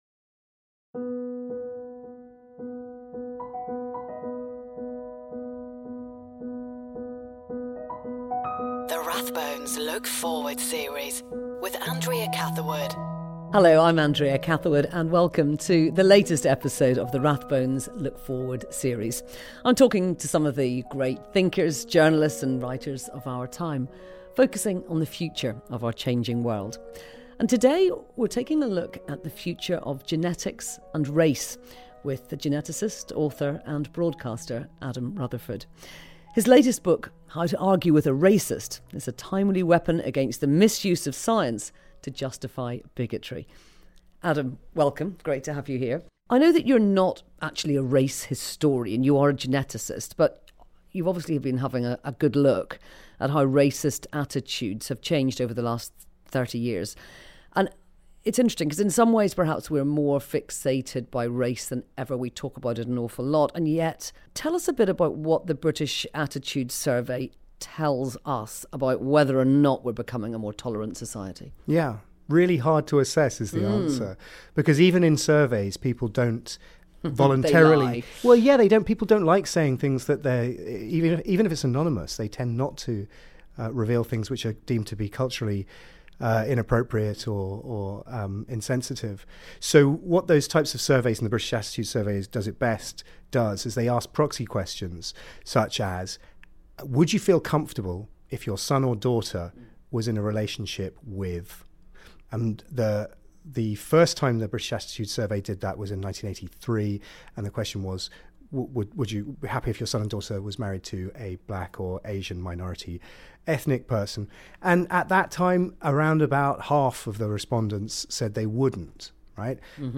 In the latest episode of the Rathbones Look forward series, Andrea Catherwood talks to geneticist, author and broadcaster Adam Rutherford about the future of genetics and race.